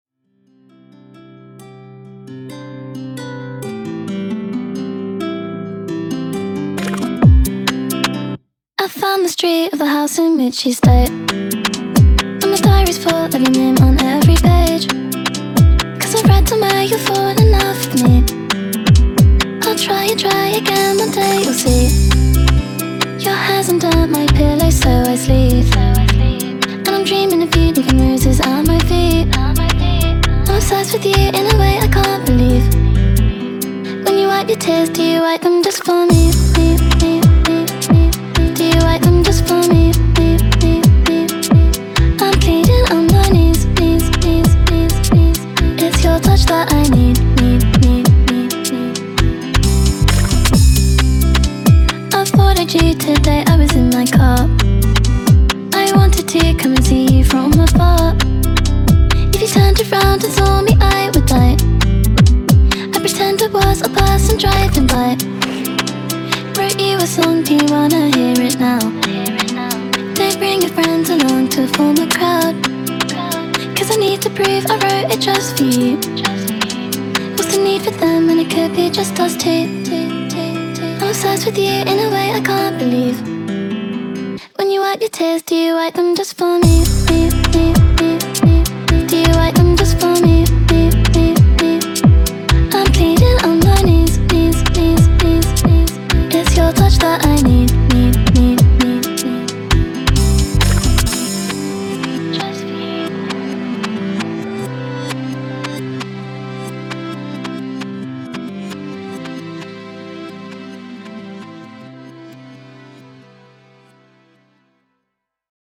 BPM133
Enjoy some old-school 2-step garage.